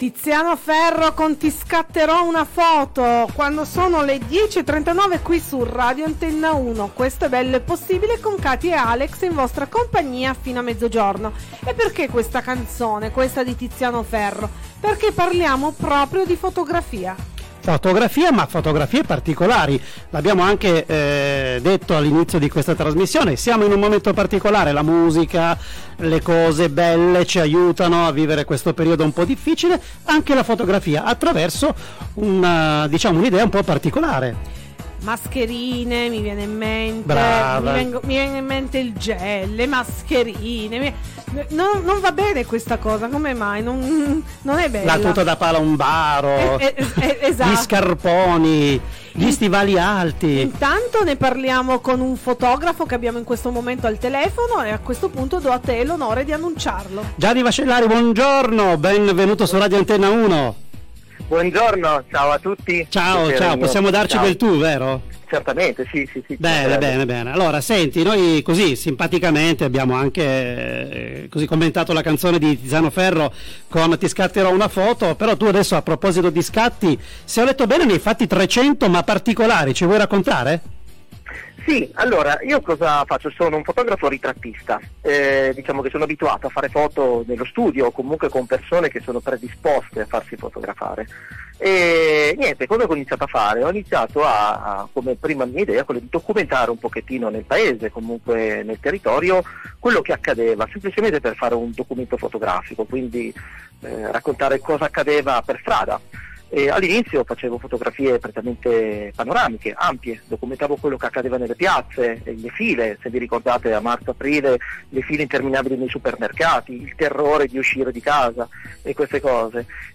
Intervista su Radio Antenna UNO
intervista_antennauno.mp3